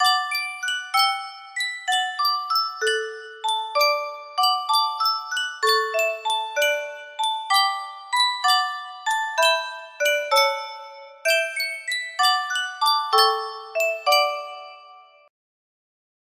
Yunsheng Music Box - Little Bo-Peep 6538 music box melody
Full range 60